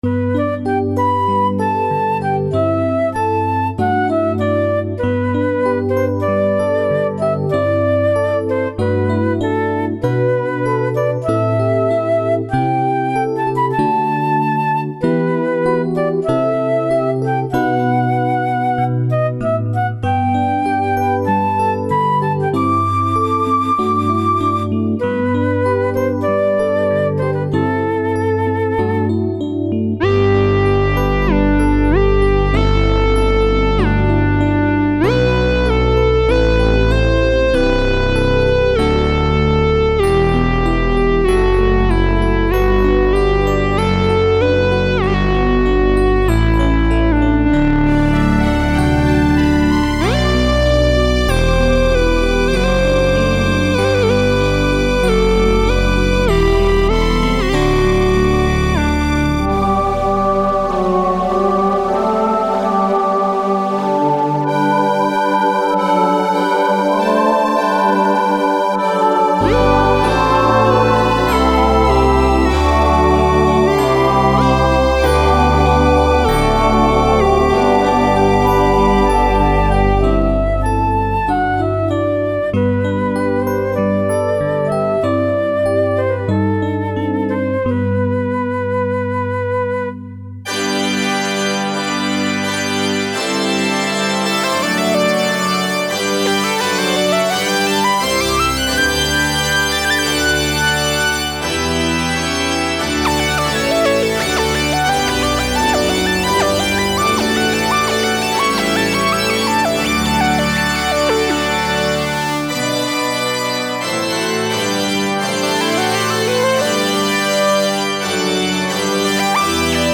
julsång från i vintras hörs Minimax ASB spela melodin på vers 1 och diverse ornament på vers 2.